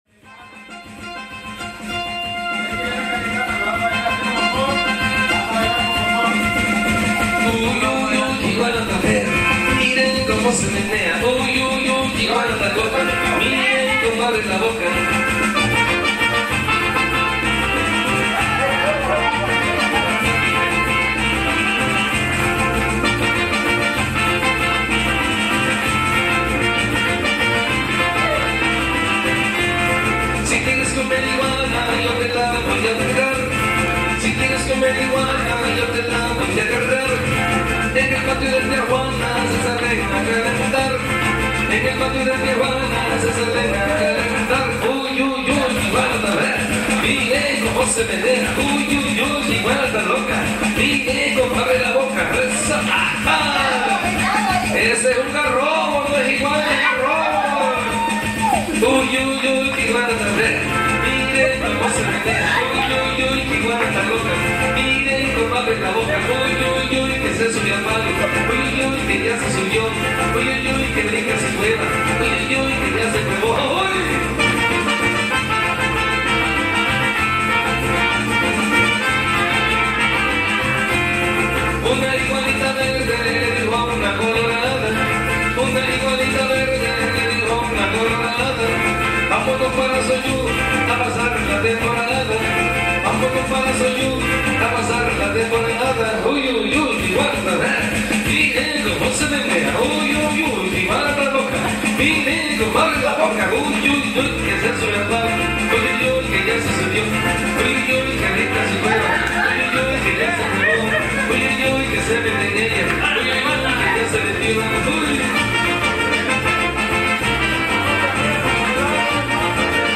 Incluye como instrumentos musicales arpa, violín, jarana chica, percusión, guitarra, requinto y casualmente una orquesta de alientos: clarinetes, saxos, trombones.
Lugar: Azoyú, Guerrero; Mexico.
Equipo: Grabadora Sony ICD-UX80 Stereo